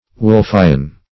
Search Result for " wolffian" : The Collaborative International Dictionary of English v.0.48: Wolffian \Wolff"i*an\, a. (Anat.)